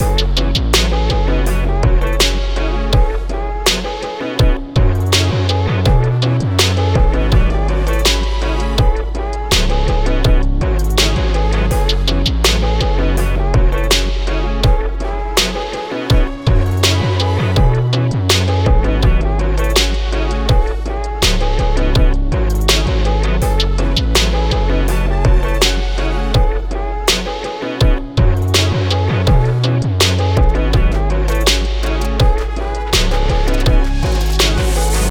Chill (Silver)
Music - Song Key
Gb Minor
Music - Bass
Music - Max Sax
Music - Shred Guitar